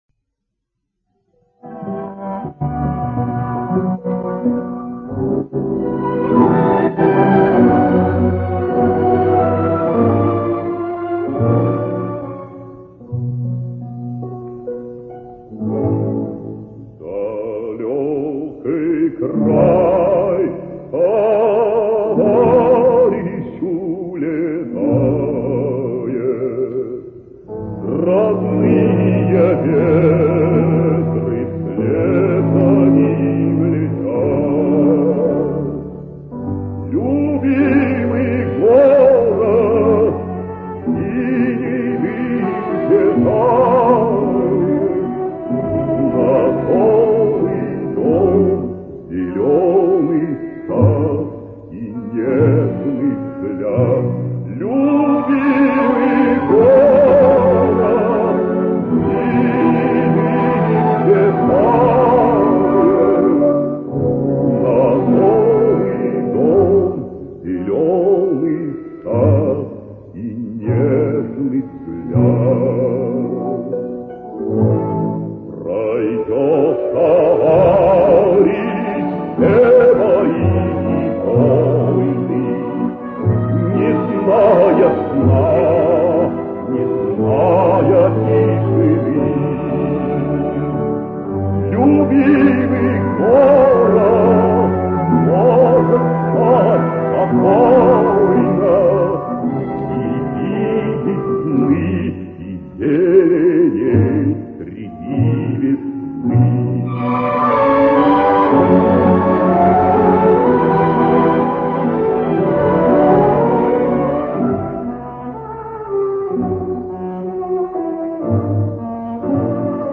Известная песня в редком исполнении.